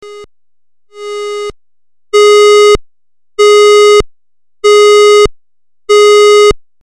EMERGENCY SIREN & PUBLIC ADDRESS SYSTEM
EMERGENCY ALERT - “Beep! Beep! Beep!”
Alert Tone.mp3